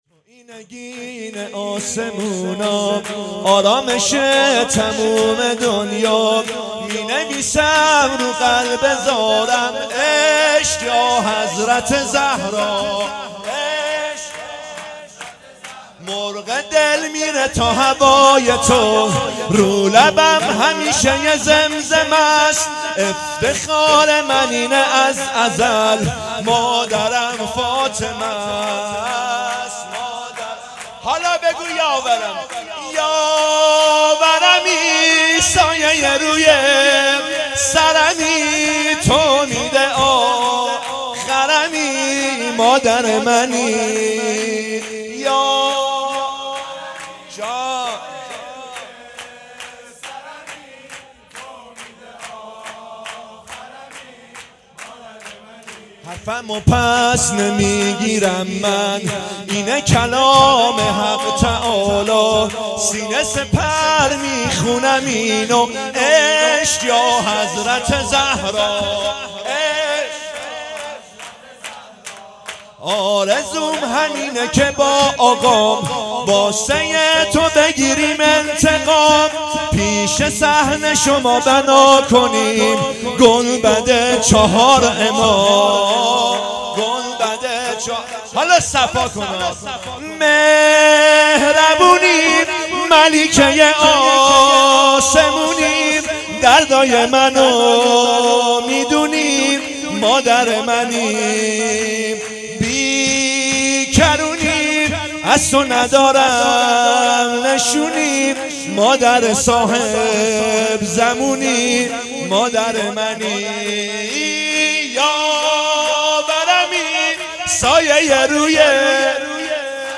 تویی نگین اسمونا _ شور
جشن ولادت حضرت زهرا سلام الله علیها